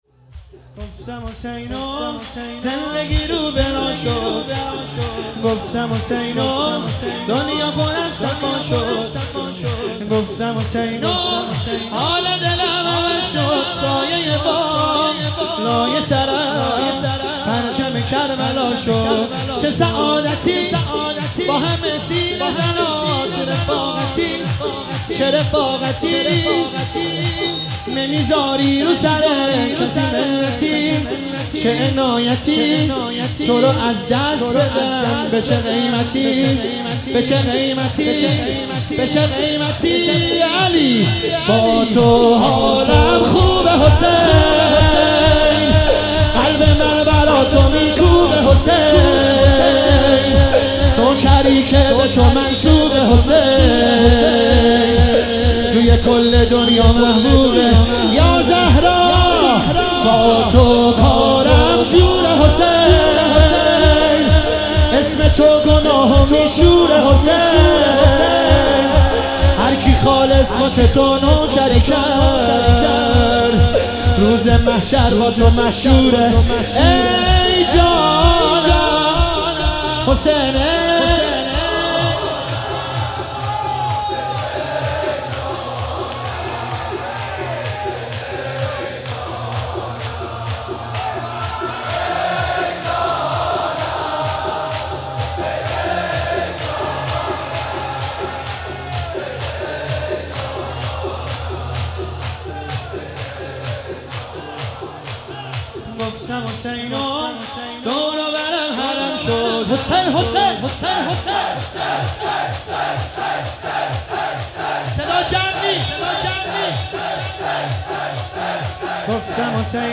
هیئت زوار البقیع تهران